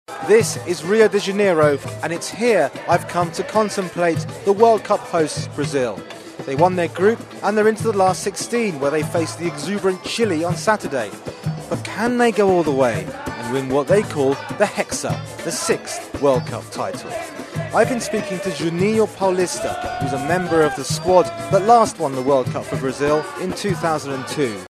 【英音模仿秀】苏神咬人遭热议 听力文件下载—在线英语听力室